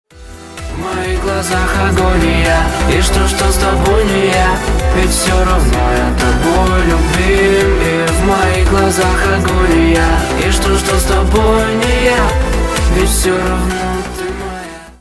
• Качество: 320, Stereo
громкие
dance